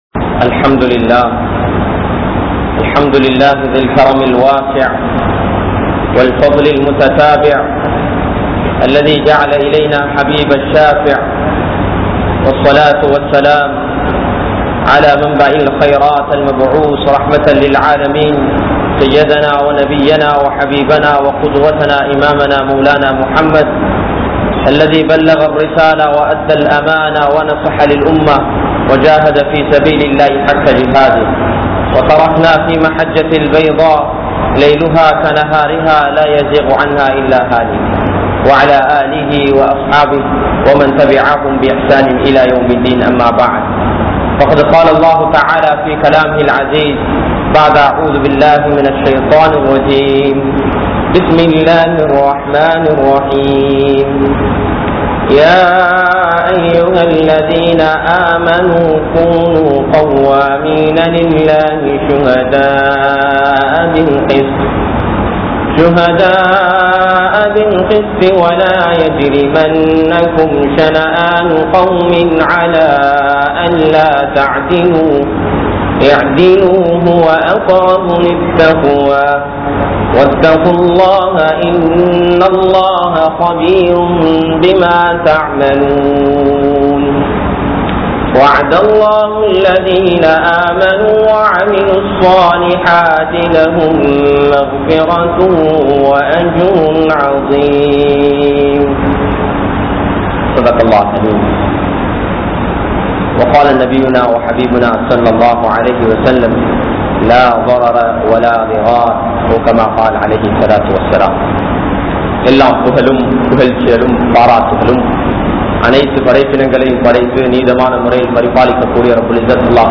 Aniyaayam (அநியாயம்) | Audio Bayans | All Ceylon Muslim Youth Community | Addalaichenai
Kollupitty Jumua Masjith